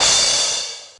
cch_perc_crash_mid_noisy_green.wav